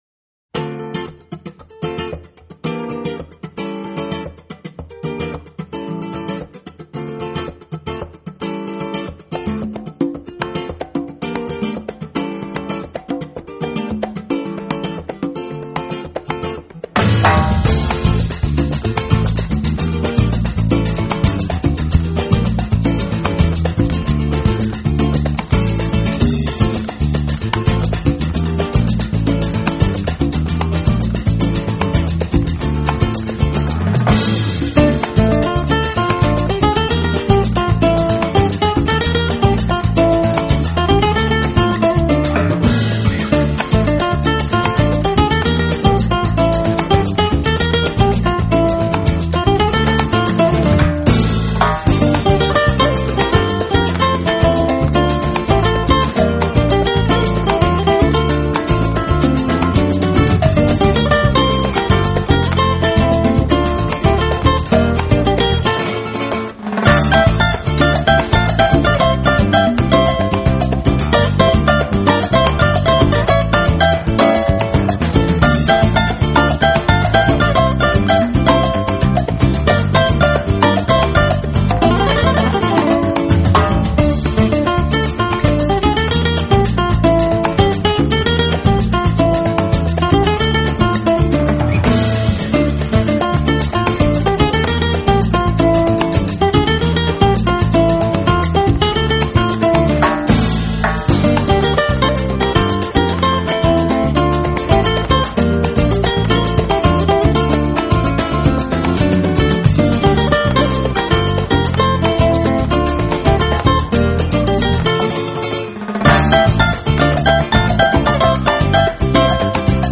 康加鼓与金贝鼓的适时加入只使得音乐更具有感染力，并没有使弗拉门戈的基调 节奏紊乱。
许多歌曲中的吉他片段弦声清亮鲜活，都有种音乐要“蹦”出来的感觉。